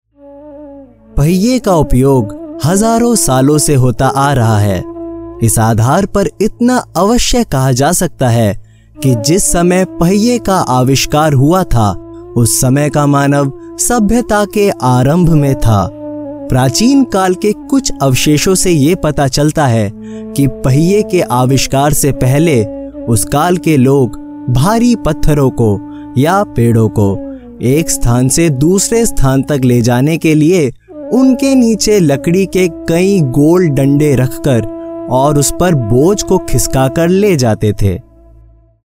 Voice Overs
HI CJ EL 01 eLearning/Training Male Hindi